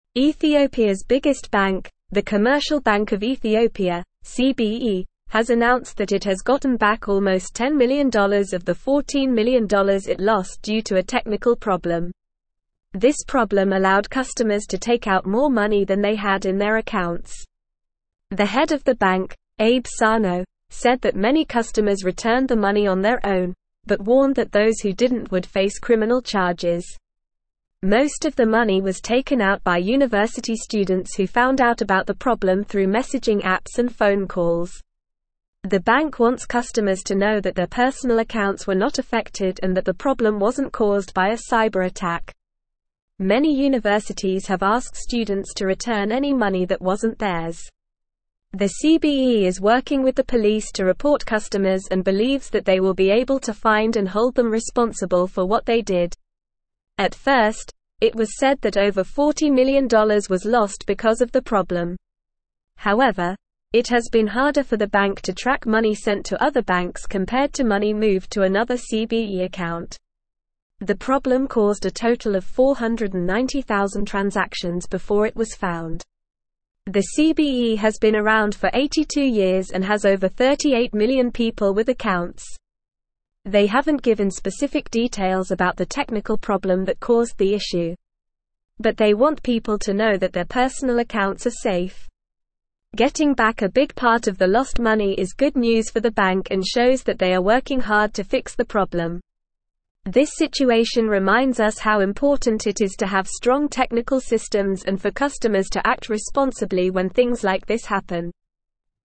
Normal
English-Newsroom-Upper-Intermediate-NORMAL-Reading-Ethiopias-Commercial-Bank-Recovers-10-Million-Lost-in-Glitch.mp3